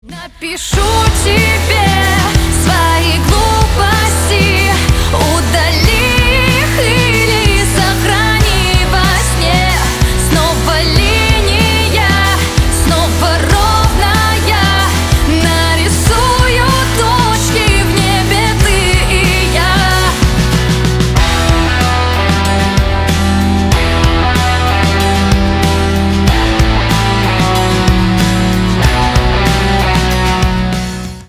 Рок Металл # Поп Музыка